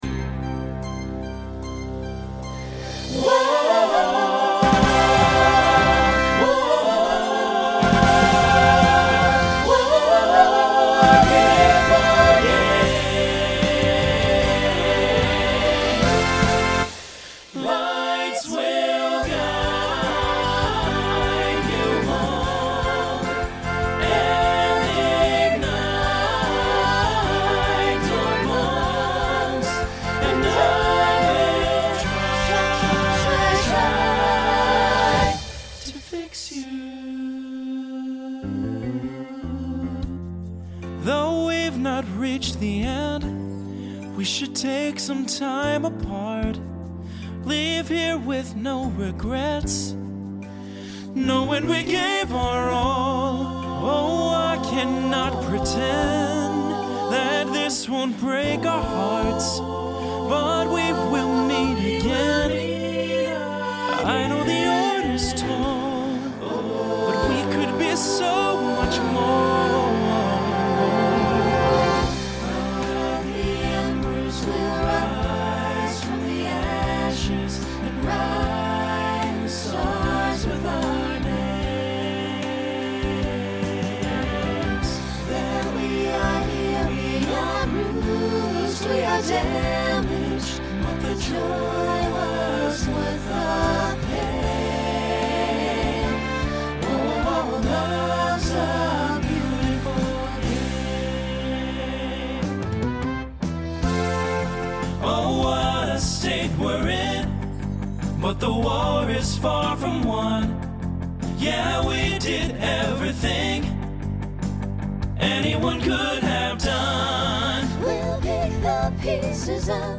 2020s Instrumental combo Genre Pop/Dance , Rock
Function Ballad , Solo Feature Voicing SATB